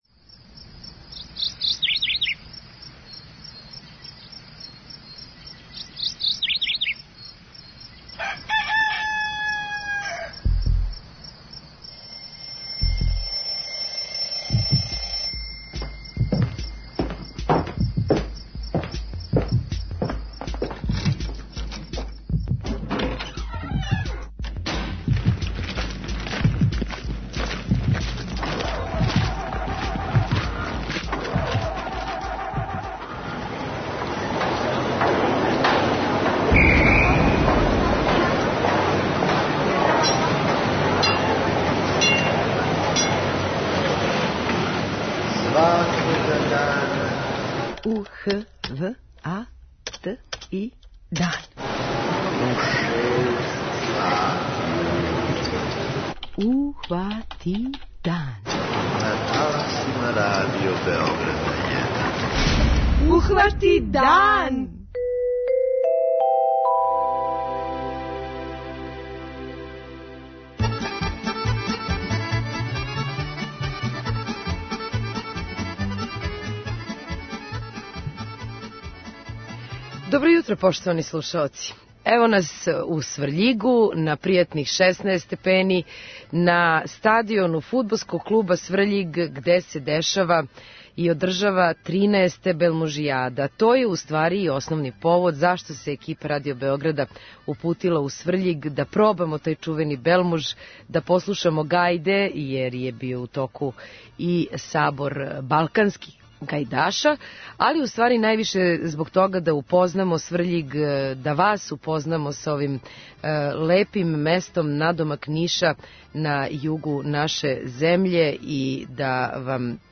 Ове суботе екипа Првог програма је у Сврљигу, а повод је 13. Белмужијада, привредна и туристичка манифестација која окупља многе привреднике и грађане из овог дела Србије.